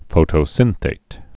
(fōtō-sĭnthāt)